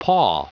Prononciation du mot paw en anglais (fichier audio)
Prononciation du mot : paw